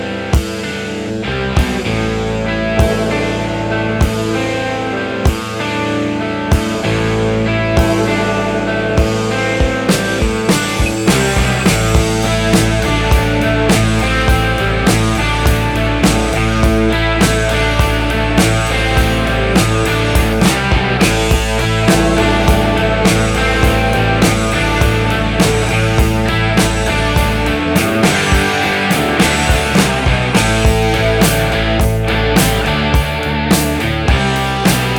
Жанр: Пост-хардкор / Хард-рок / Рок